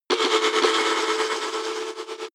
Royalty free music elements: Tones